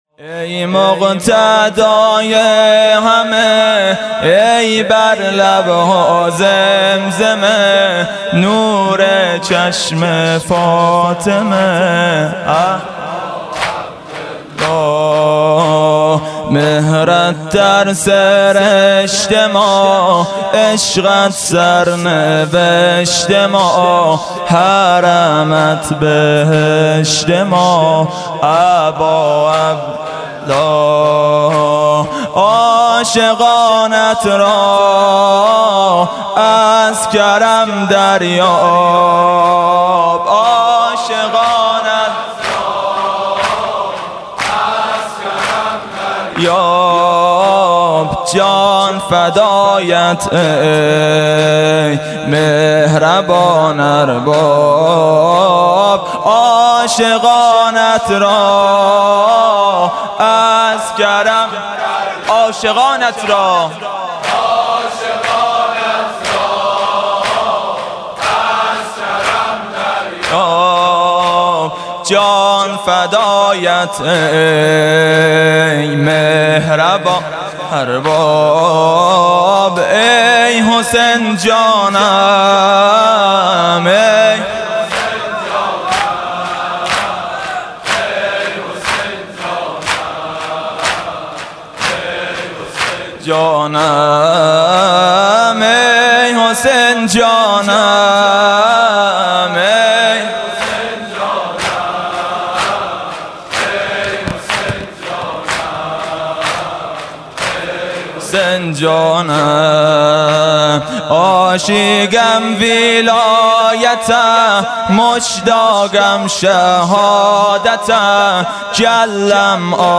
«محرم 1396» (شب پانزدهم) زمینه: ای مقتدای همه ای بر لب ها زمزمه